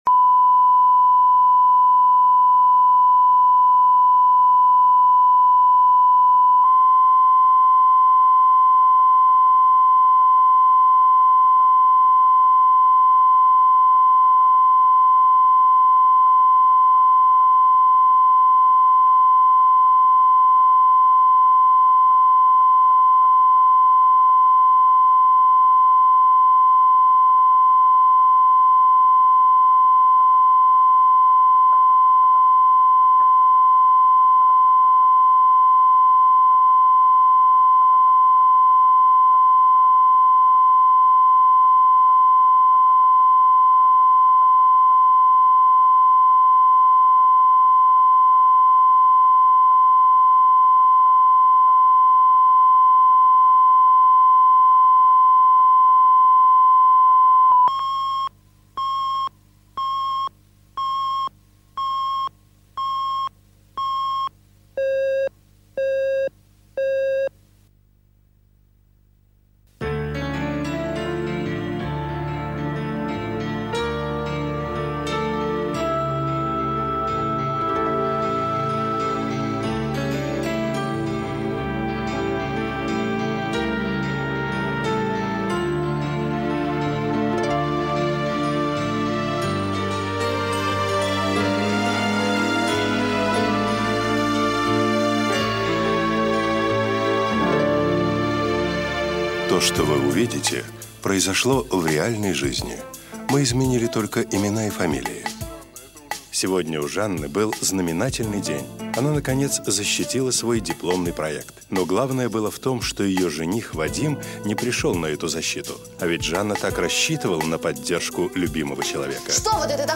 Аудиокнига Ревность